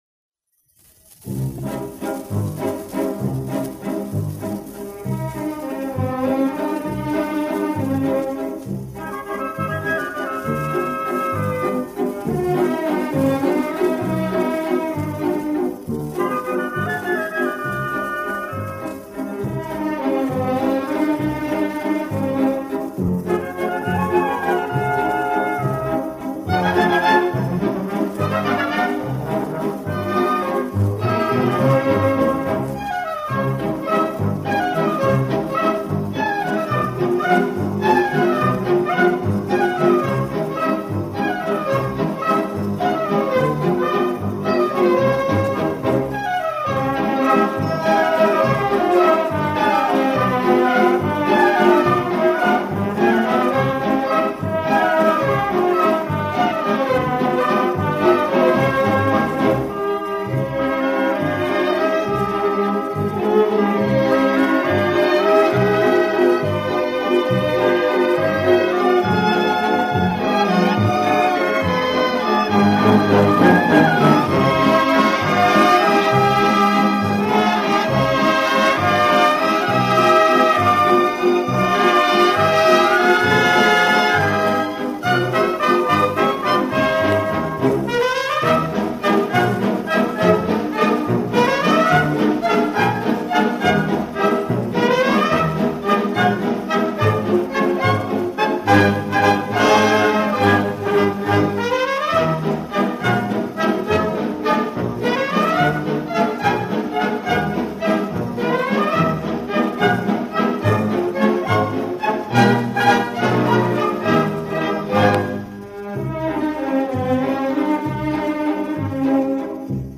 Предлагаю небольшой вечерний концерт старых вальсов в исполнении духовых оркестров.